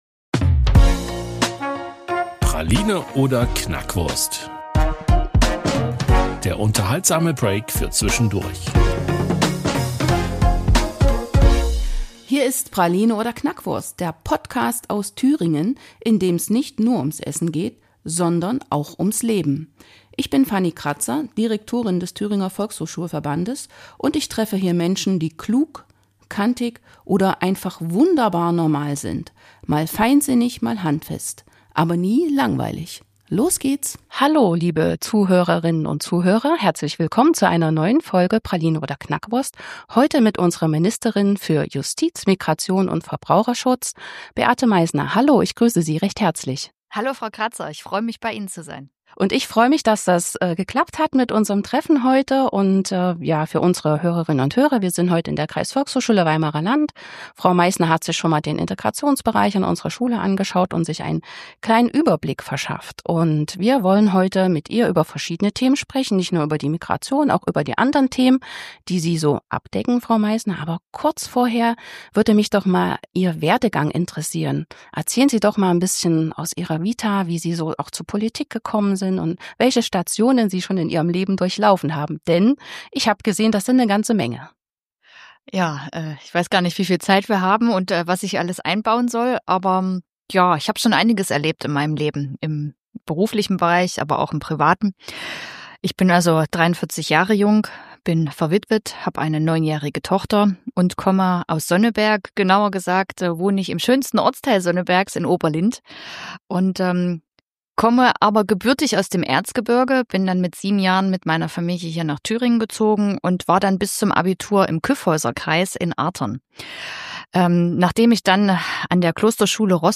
In dieser Folge ist die Thüringer Ministerin für Justiz, Migration und Verbraucherschutz, Beate Meißner zu Gast. Gemeinsam sprechen wir nicht nur über ihren spannenden Werdegang und ihre Motivation für die Politik, sondern tauchen auch tief in aktuelle Herausforderungen wie Migration, Integration und den Rechtsstaat ein.